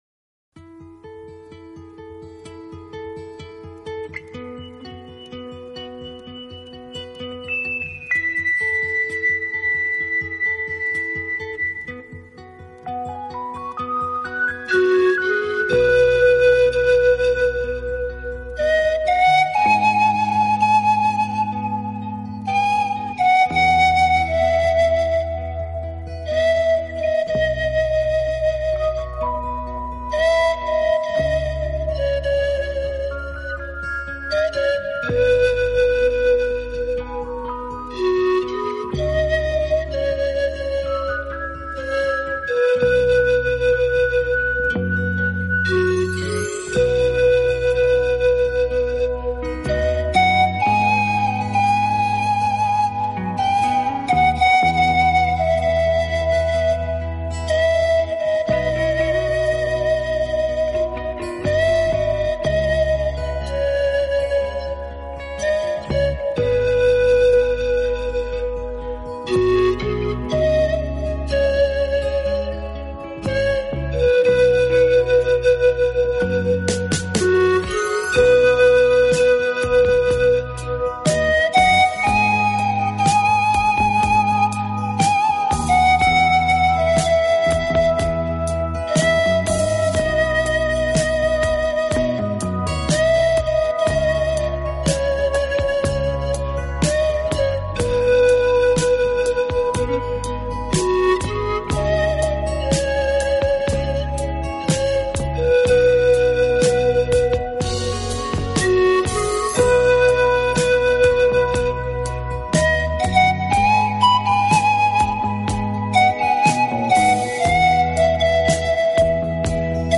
柔美排箫
虚幻、飘渺的音色。